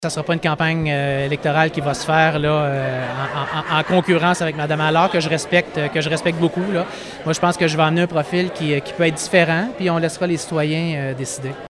En conférence de presse lundi au Club de golf Godefroy, devant près d’une cinquantaine de personnes, il a souligné qu’il ne critiquait pas la gestion de la mairesse Lucie Allard, mais qu’il voulait amener sa propre vision du développement de la ville.